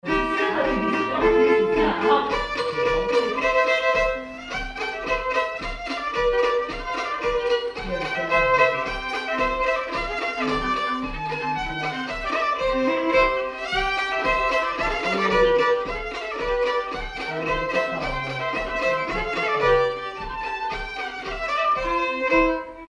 Polka
Villard-sur-Doron
danse : polka
circonstance : bal, dancerie
Pièce musicale inédite